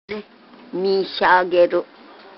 山口方言ライブラリ